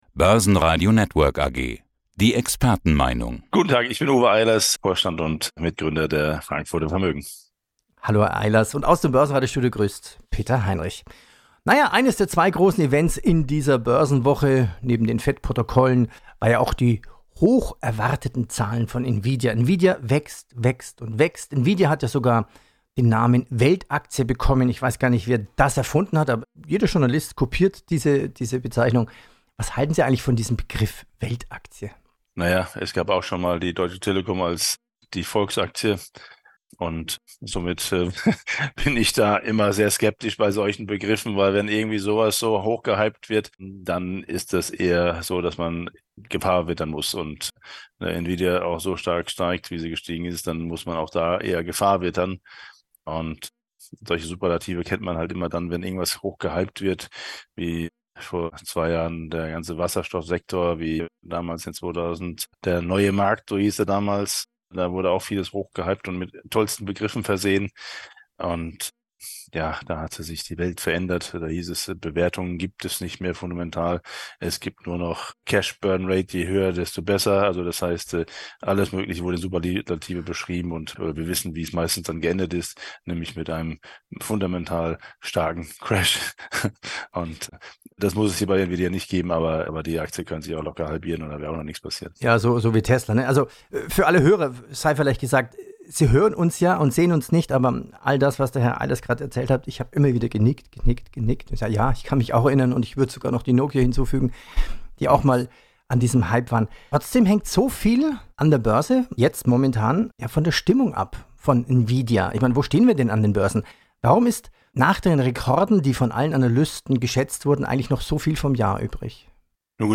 Radiobeitrag: Nvidia die „Weltaktie“ – Man muss die Gefahr vor Nvidia wittern!